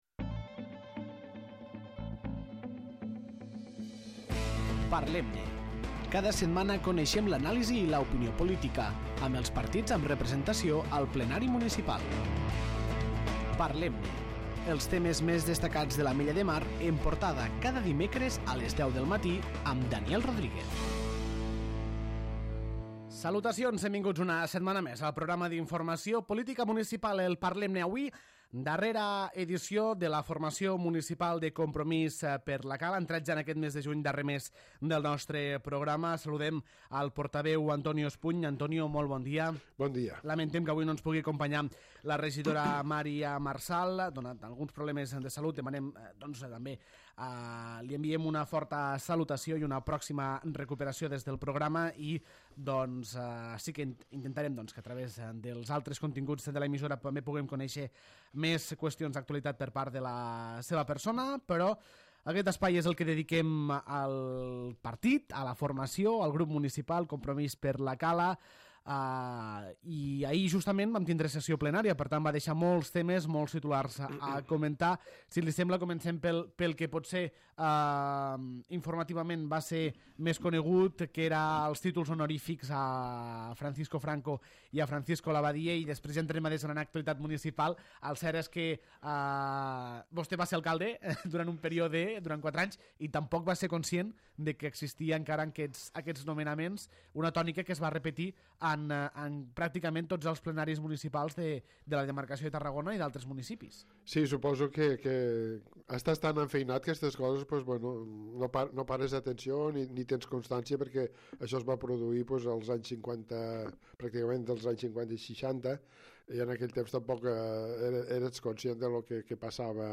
Aquesta setmana, al Parlem-ne ens ha acompanyat el regidor del grup municipal de Compromís per la Cala - Candidatura de Progrés, Antonio Espuny.